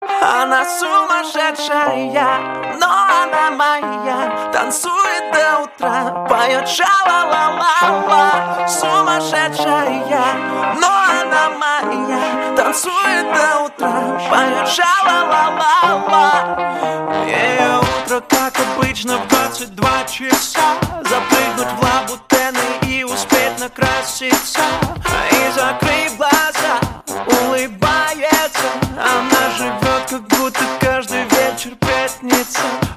• Качество: 112, Stereo
поп
мужской вокал